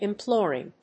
音節im・plór・ing 発音記号・読み方
/‐plˈɔːrɪŋ(米国英語), ˌɪˈmplɔ:rɪŋ(英国英語)/